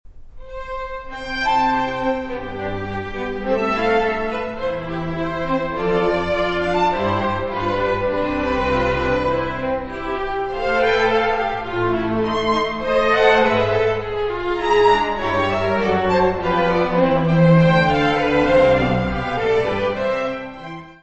Music Category/Genre:  Classical Music
Andante.